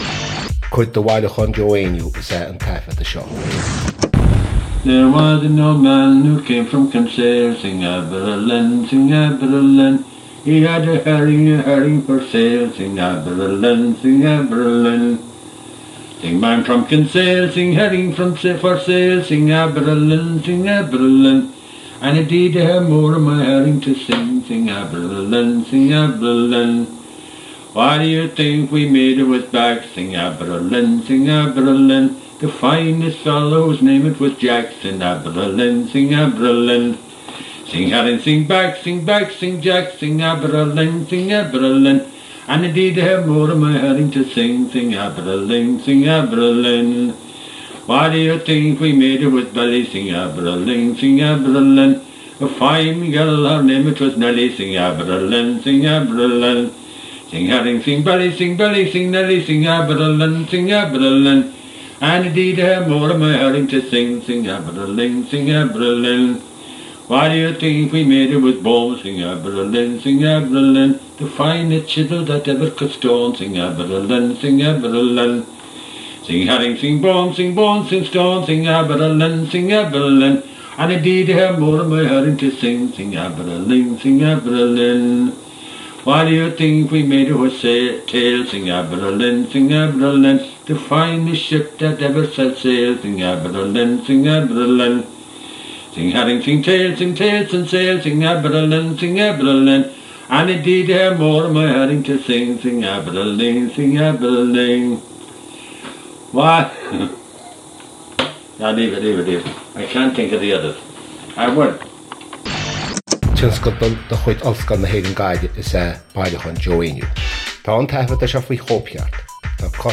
• Catagóir (Category): song.
• Ainm an té a thug (Name of Informant): Joe Heaney.